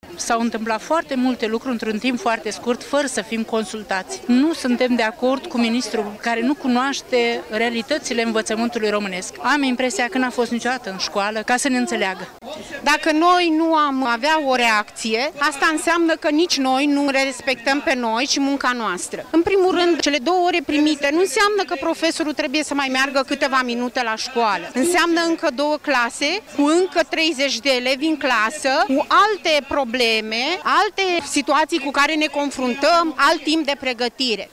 1-sept-ora-15-cadre-didactice.mp3